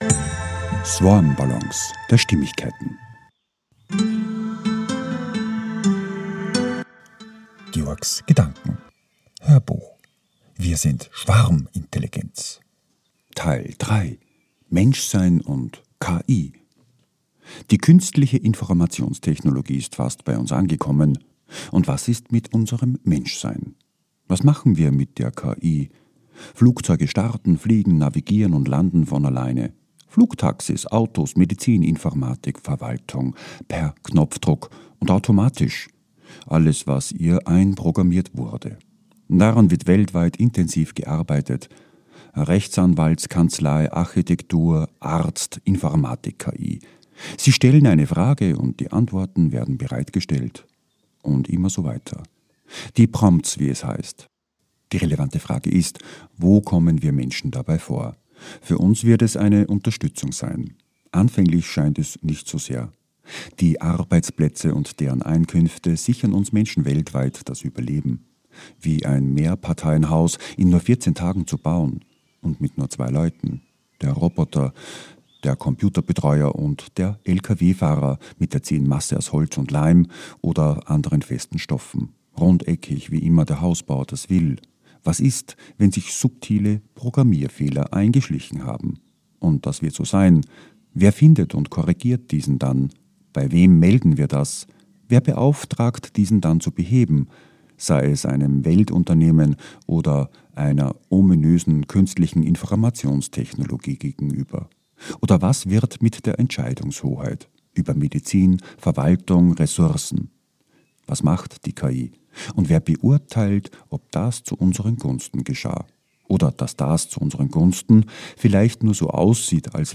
HÖRBUCH - 003 - WIR SIND SCHWARMINTELLIGENZ - MENSCHSEIN & KI